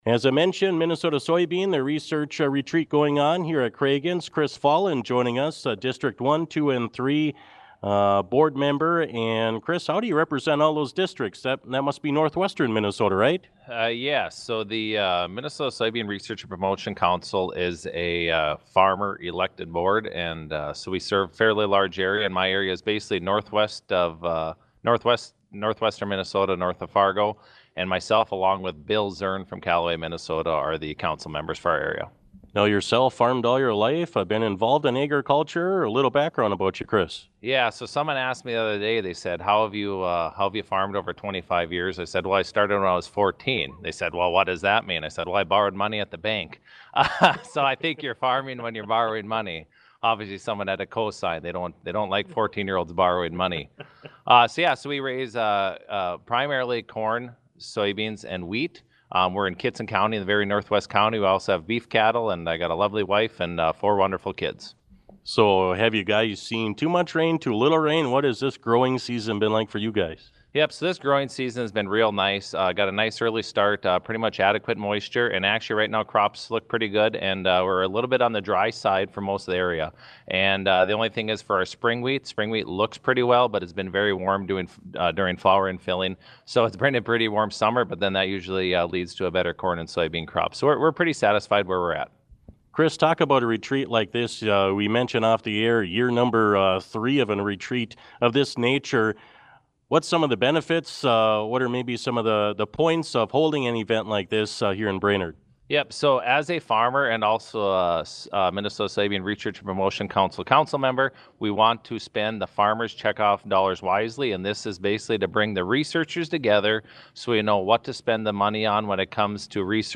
Noontime News was LIVE from the event
Listen to our interviews as MN Soybean Research & Promotion Council board members joined the broadcast: